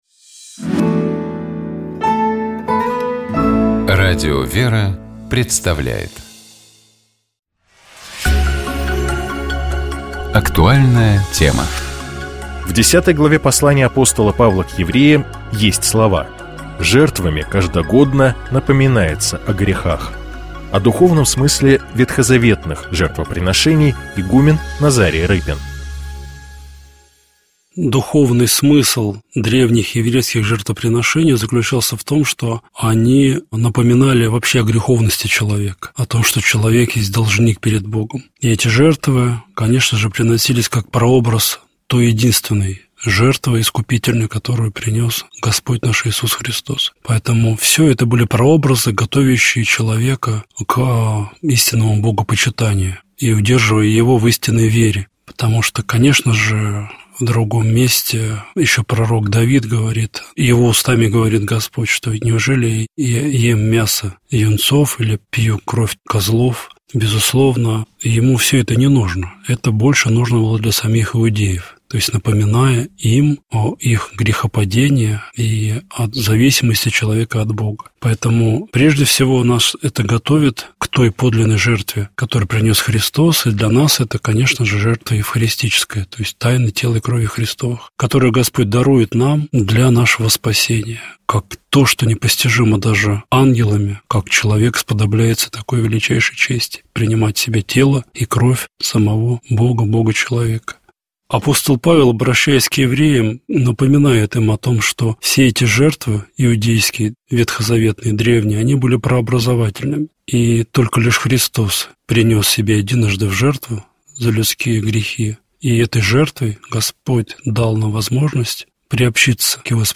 Комментирует епископ Переславский и Угличский Феоктист.